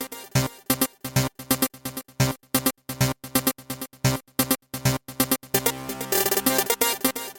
双耳节拍 " 79 Au Gold Aurum 39,29
声道立体声